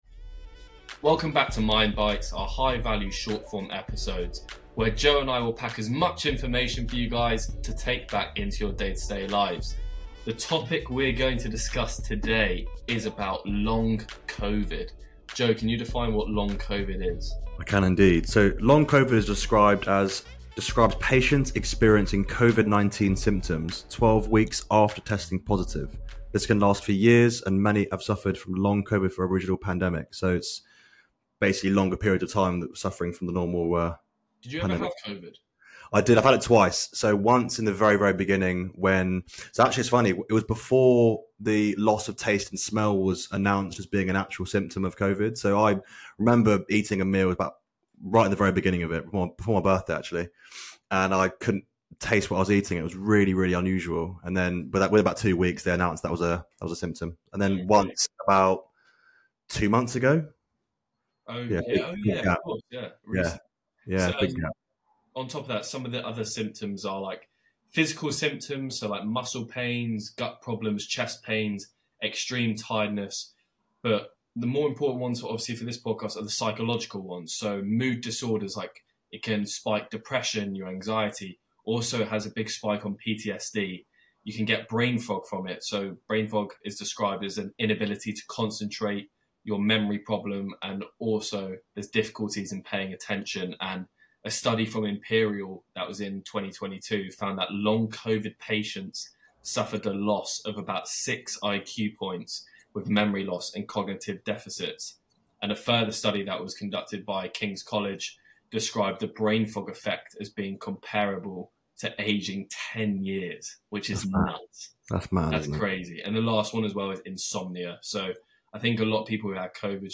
This conversation is a candid look at the physical and psychological battles, the skepticism met by patients, and the censorship experienced while trying to discuss these issues.